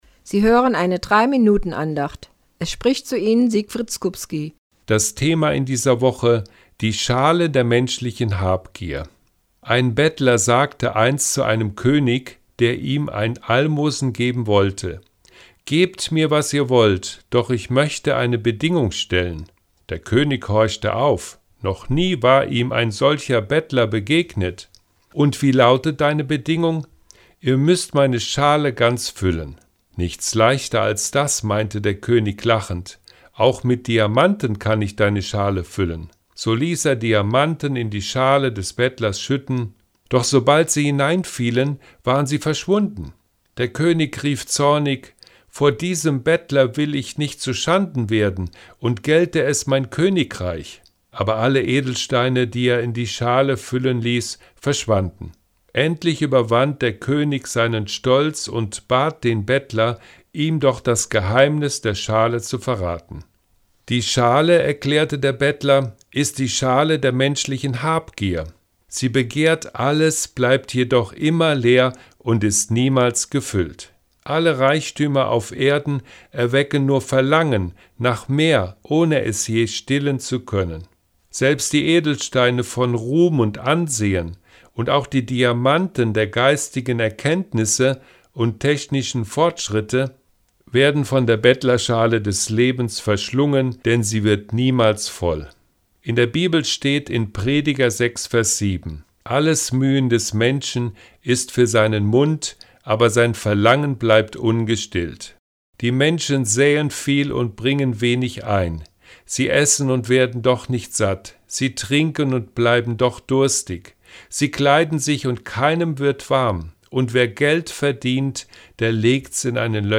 WEB-ANDACHT